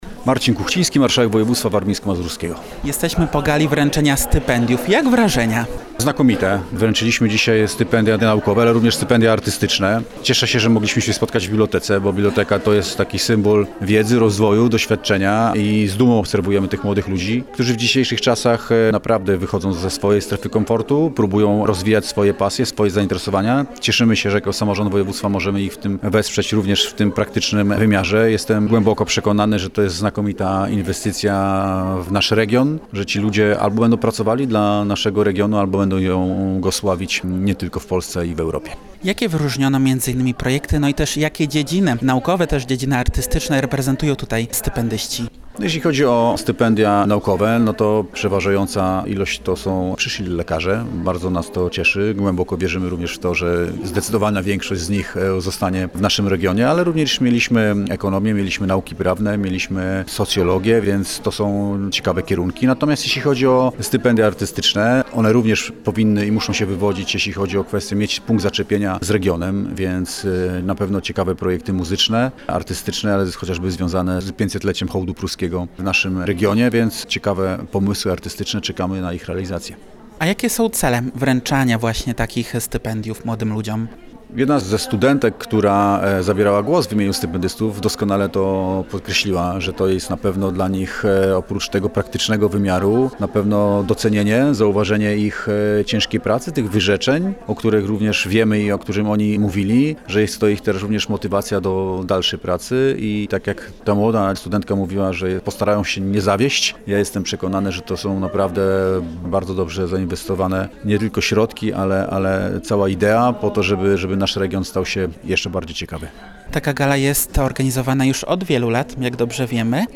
– zwracał się do tegorocznych stypendystów Marcin Kuchciński.